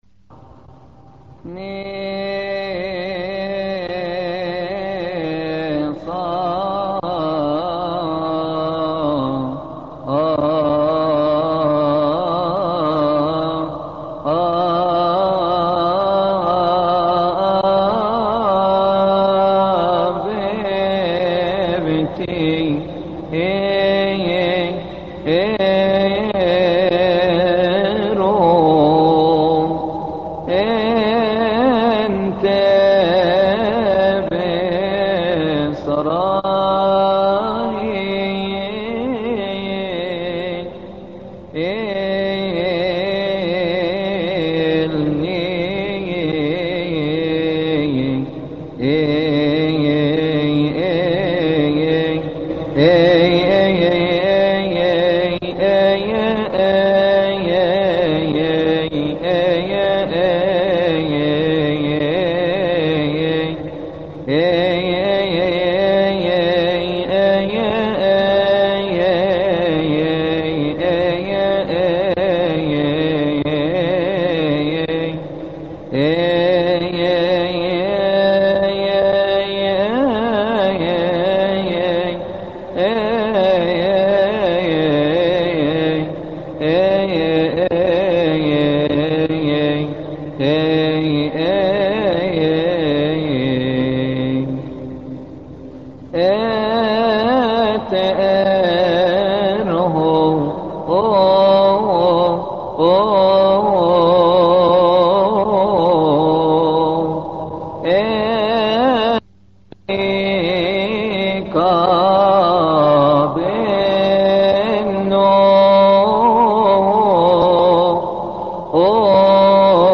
لحن ني صافيف تيرو